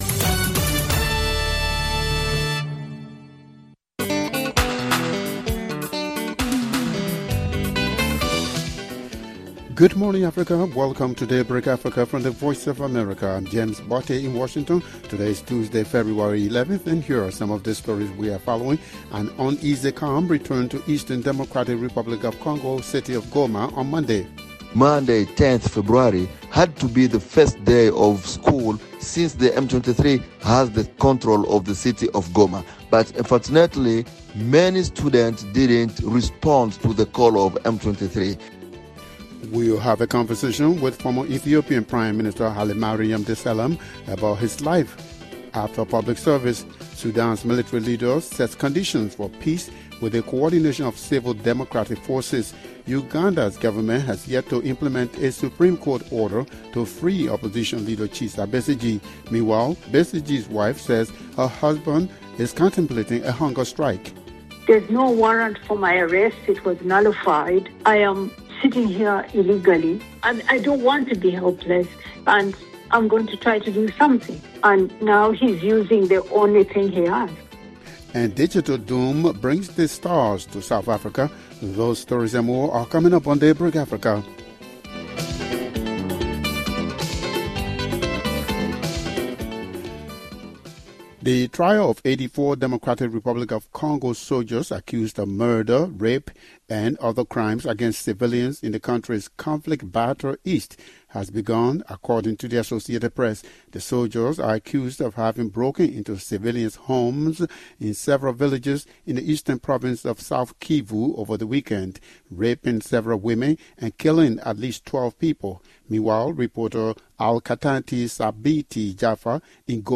On Daybreak Africa: The United Nations accuses Sudan's paramilitary Rapid Support Forces of blocking aid to the war-torn country's famine-threatened Darfur region, according to the French News Agency (AFP). Plus, an uneasy calm returned to eastern Democratic Republic of Congo city of Goma. We’ll have a conversation with former Ethiopian Prime Minister Hailemariam Desalegn about his life after public service.